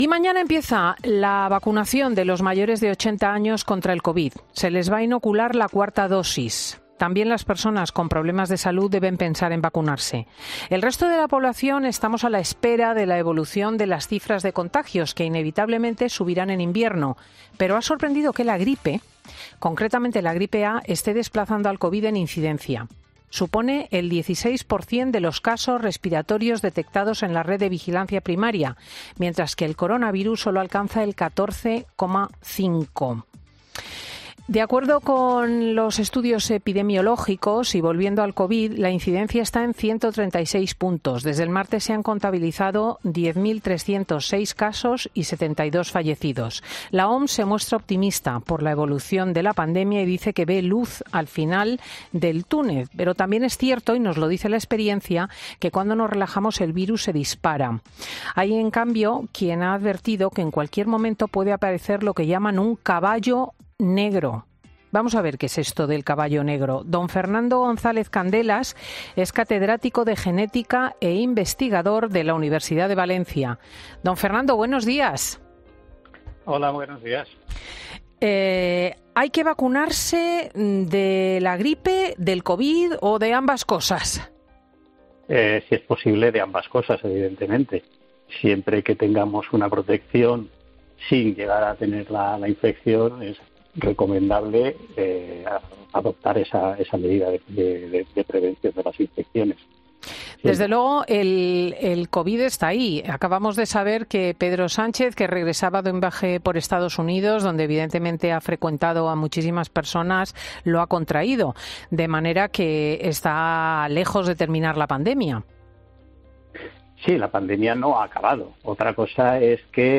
Por eso, en Fin de Semana, hemos querido hablar con un experto para que nos aclare cómo está el virus a estas alturas y qué podemos esperar en el futuro.